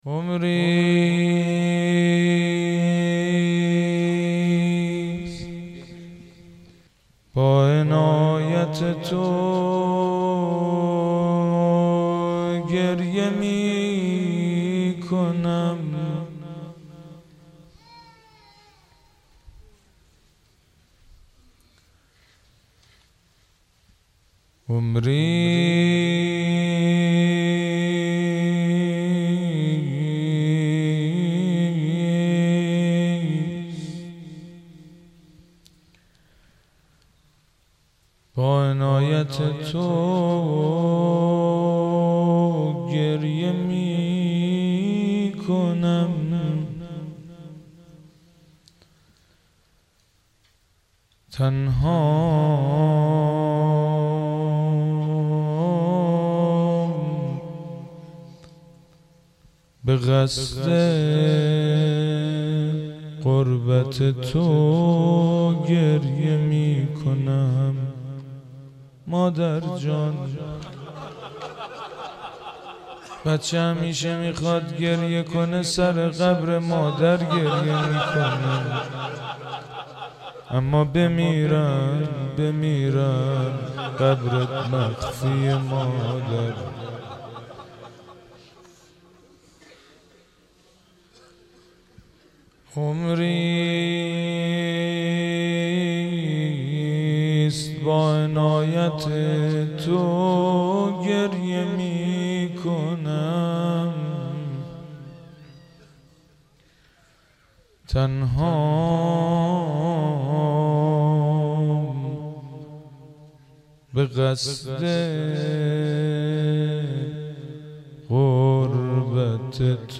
دانلود مداحی پرچم مشکی روضه ها - دانلود ریمیکس و آهنگ جدید